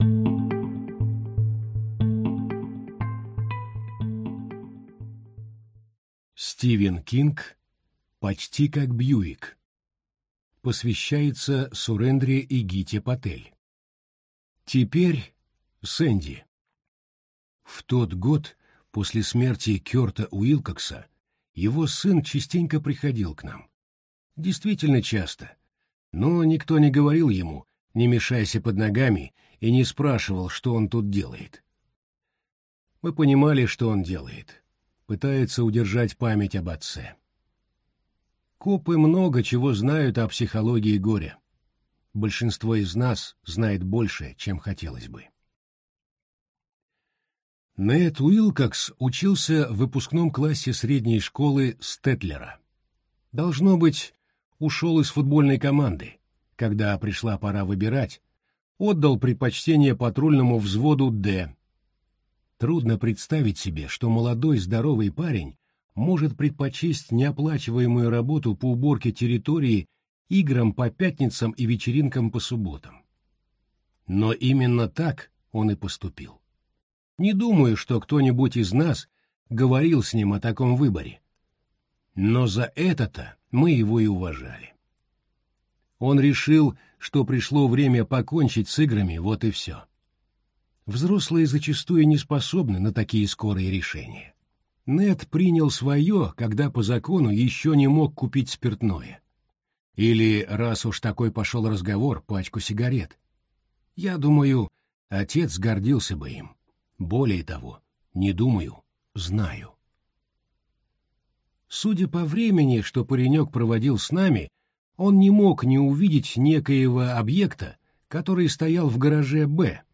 Аудиокнига Почти как «бьюик» - купить, скачать и слушать онлайн | КнигоПоиск